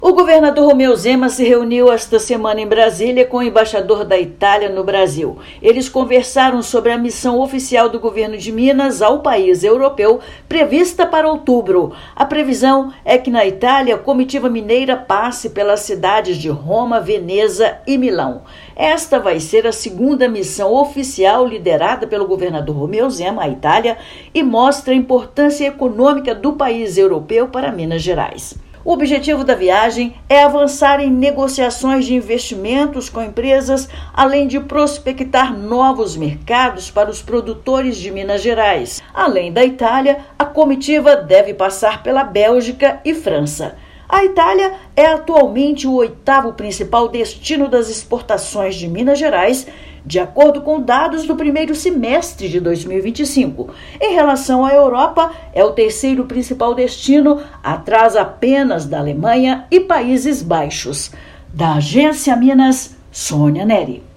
Encontro diplomático abordou parceria estratégica entre mineiros e italianos em Brasília (DF). Ouça matéria de rádio.